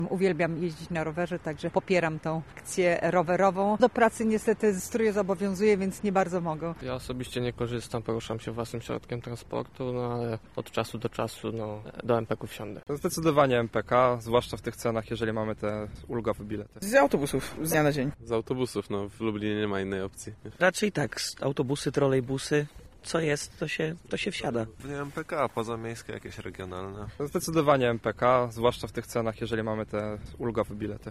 Sonda rowery  – mówią mieszkańcy o transporcie publicznym.
Sonda-rowery-Mixdown-1.mp3